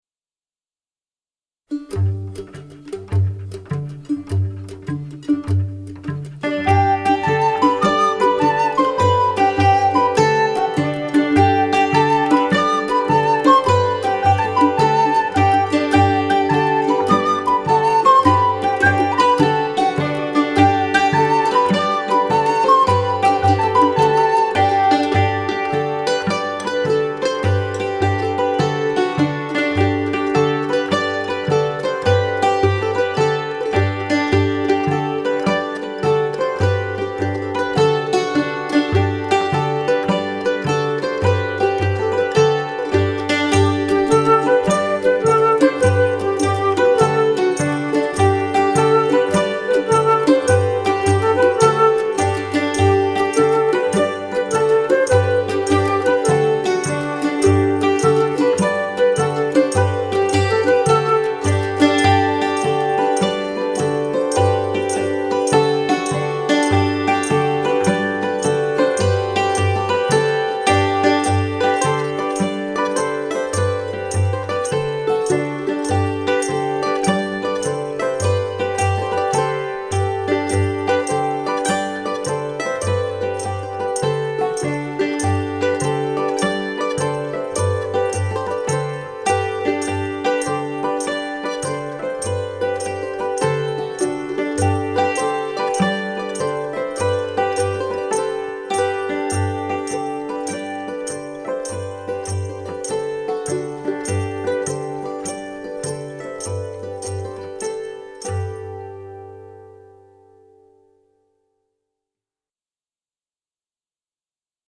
DIGITAL SHEET MUSIC - HAMMERED DULCIMER SOLO